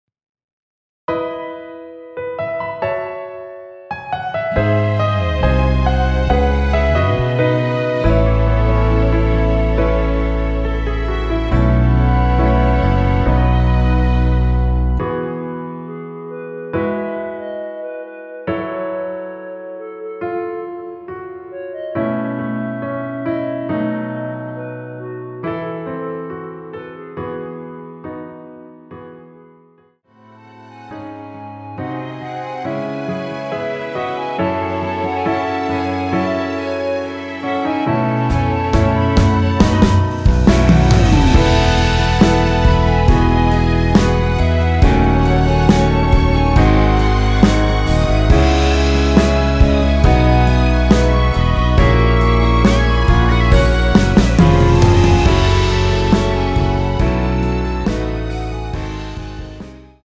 원키에서(+4)올린 (1절+후렴)으로 진행되는 멜로디 포함된 MR입니다.(본문의 가사와 미리듣기 확인)
멜로디 MR이라고 합니다.
앞부분30초, 뒷부분30초씩 편집해서 올려 드리고 있습니다.